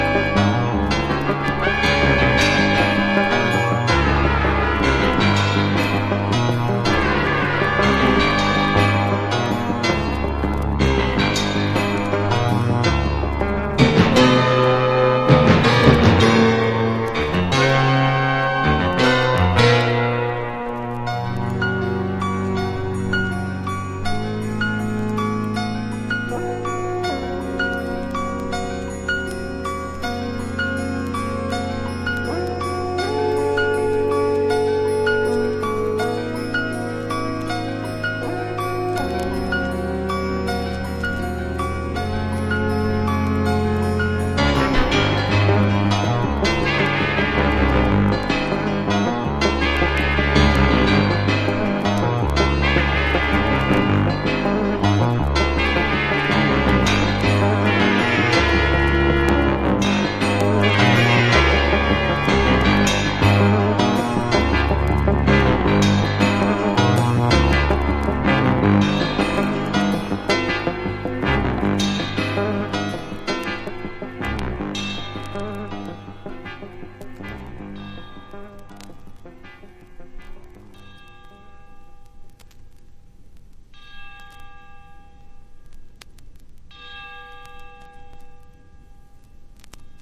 1. 70'S ROCK >
視覚をも刺激する仏産エレクトロ・プログレ盤！
PSYCHEDELIC / JAZZ / PROGRESSIVE